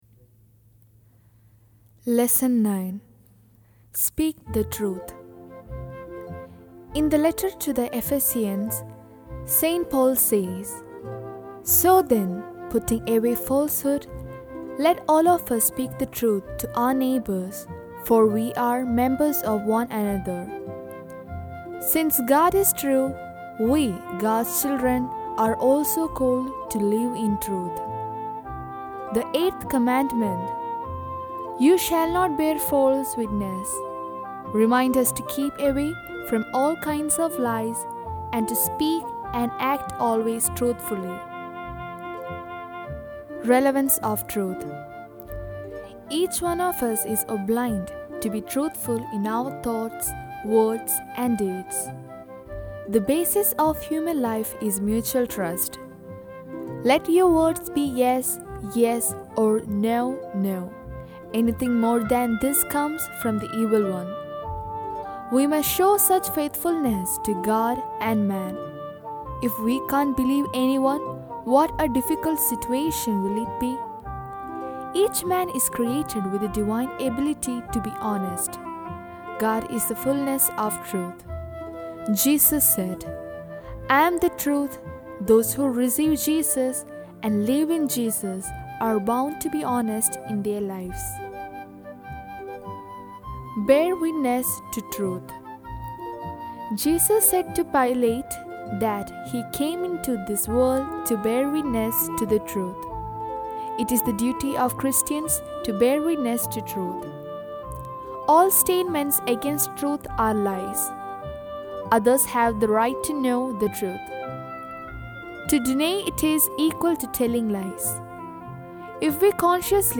lesson